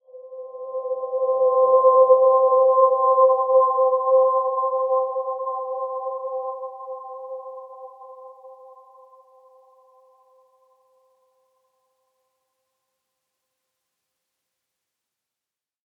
Dreamy-Fifths-C5-p.wav